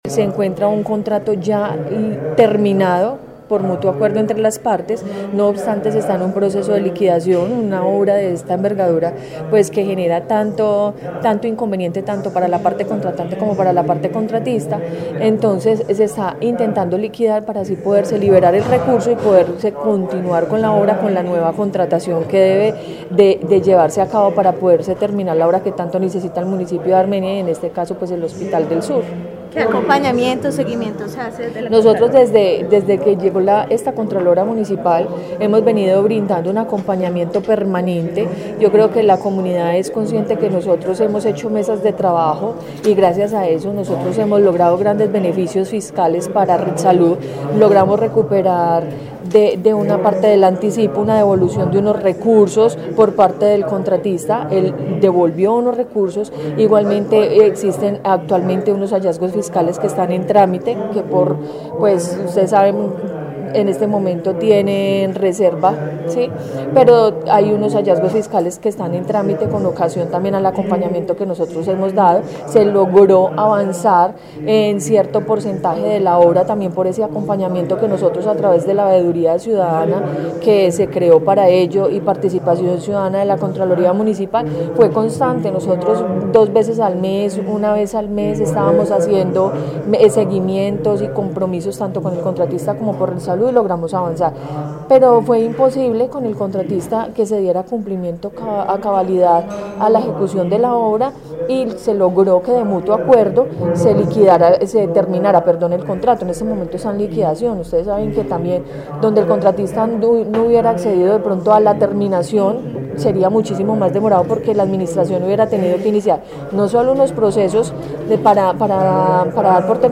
Contralora municipal de Armenia